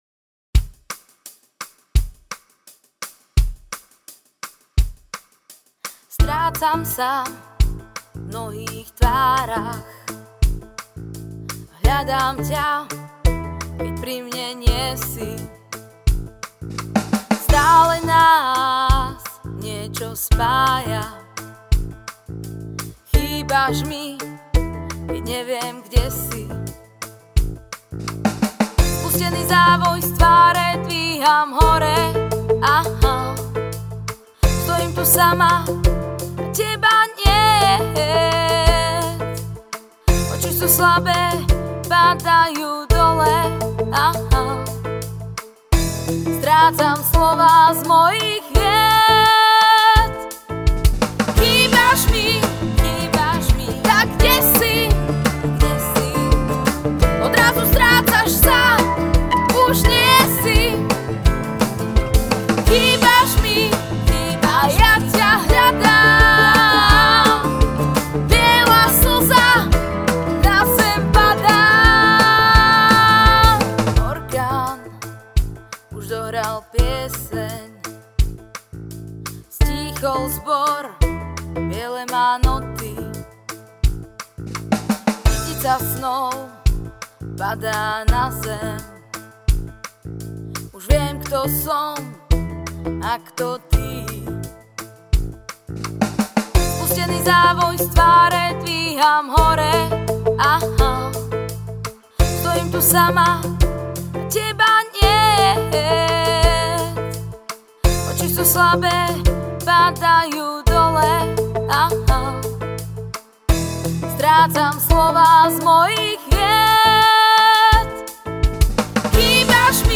Malá ukážka vytvorená ako pokus o popík  v domácom štúdiu, tomu zodpovedajú texty s tematikou srdečných záležitostí/bolesti duše spôsobené láskou/ cool
Spev je agresívny a niečo s tým určite bude treba poriešiť, ale našťastie je to len demo. To, že sa nahrávalo doma sa prejavilo.